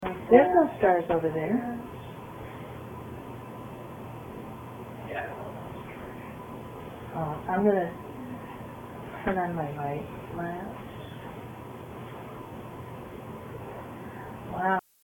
A basement observation session revealed this voice. It is not one of us and was not heard at the time.